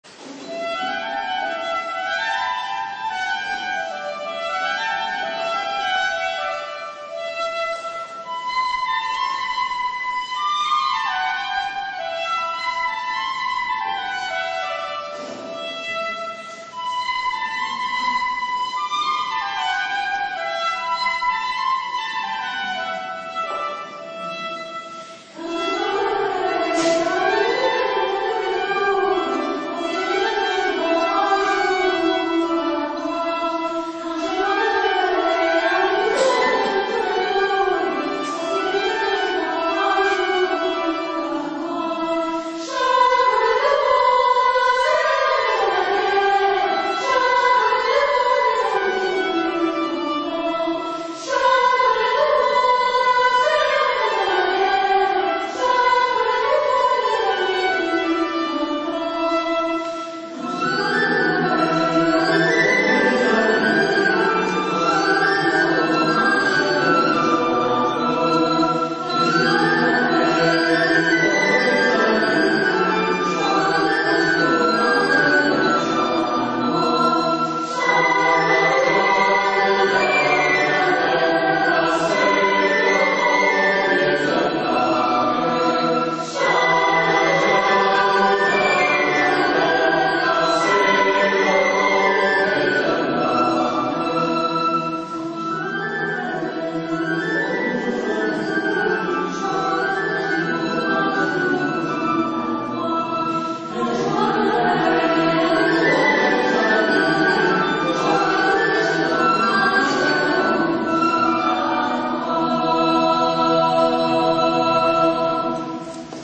Medievale